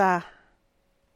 rda[rdàa]